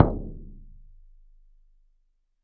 打击乐 " 萨满手架鼓13
描述：萨满手架鼓 录音室录音 Rode NT1000AKG C1000sClock音频C 009ERF边界麦克风 收割者DAW
标签： 宝思兰鼓 的PERC 巫师 敲击 萨满 打击乐器 框架
声道立体声